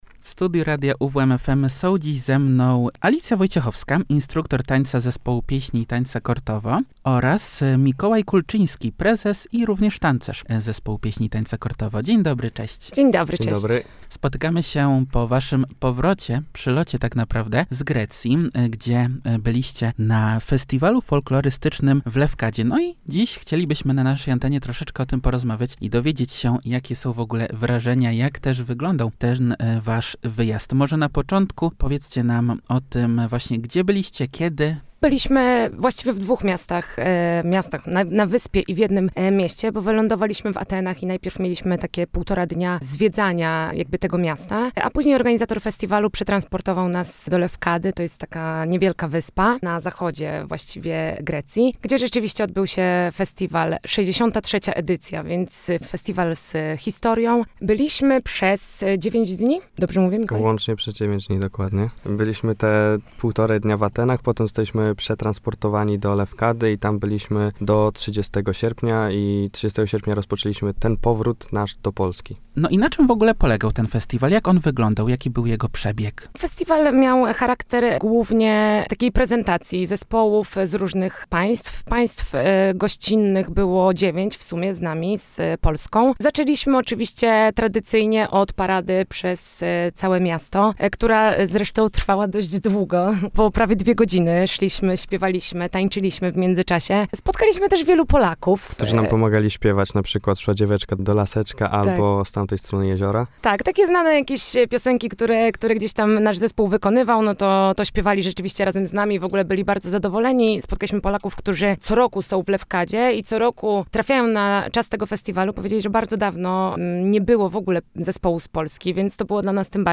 Jak wyglądał przebieg Festiwalu w Grecji i z jakim odbiorem spotkali się tancerze? Posłuchajcie całej rozmowy!